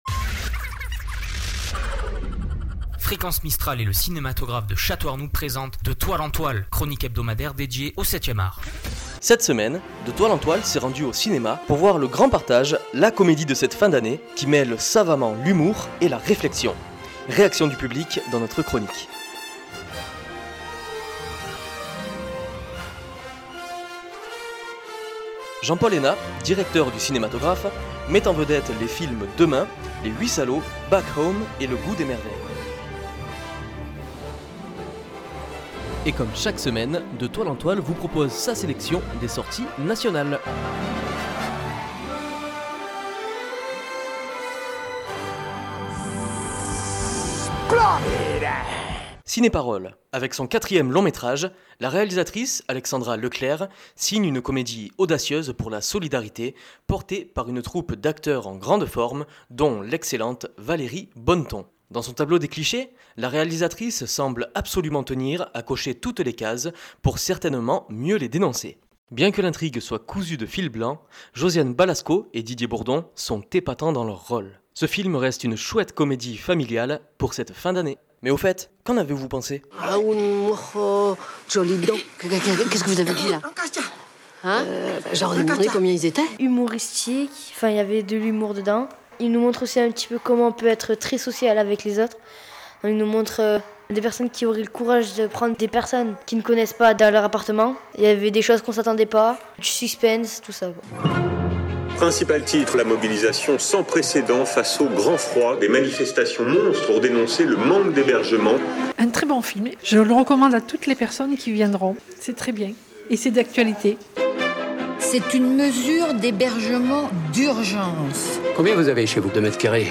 Nous entendrons l'avis du public à la sortie des salles obscures.